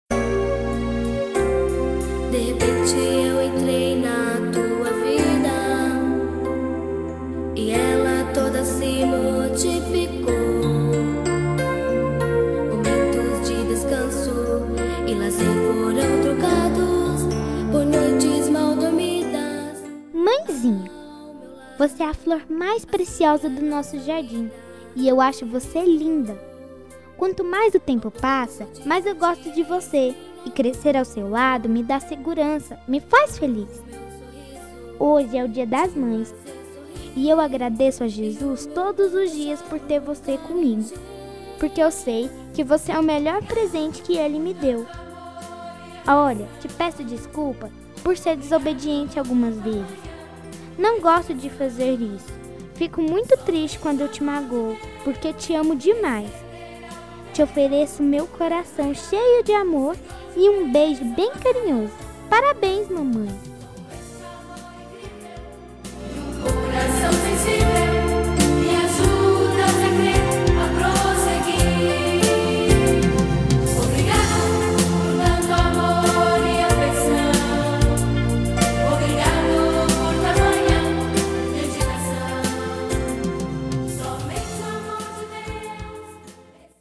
Voz Menina